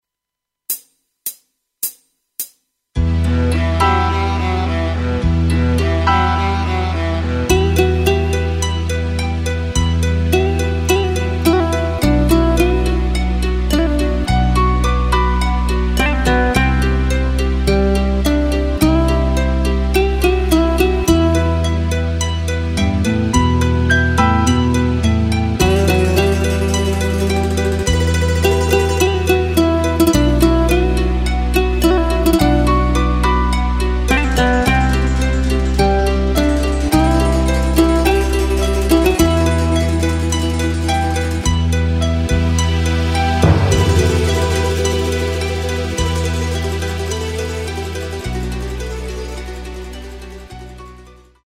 Tempo:         106.00
Tonart:            Em
Ohne Flöten-Stimme!
Playback mp3 Demo